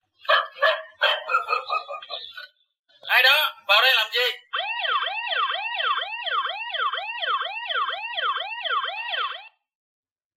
Âm thanh Chuông Cảnh Báo Ai đó vào đây làm gì?
Thể loại: Tiếng chuông, còi
Description: Âm thanh tiếng còi cảnh báo, chó sủa “Ai đó vào đây làm gì?” là tín hiệu cảnh báo an ninh, nhắc nhở xâm nhập trái phép. Loa thông báo phát ra âm thanh cảnh báo, còi chống trộm, báo động khẩn cấp, giúp bảo vệ khu vực, ngăn chặn kẻ lạ.
am-thanh-chuong-canh-bao-ai-do-vao-day-lam-gi-www_tiengdong_com.mp3